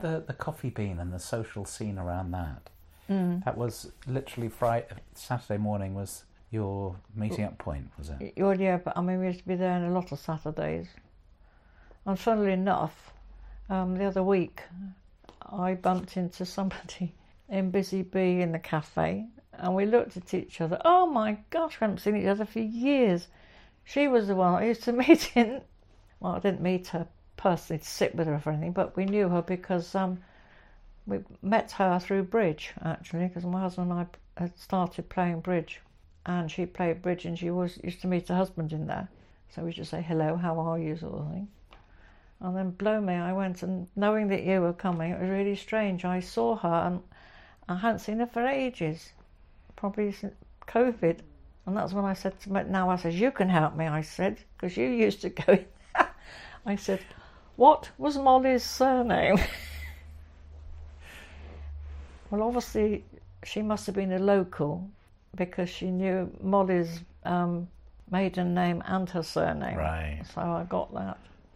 Packs' People Oral History project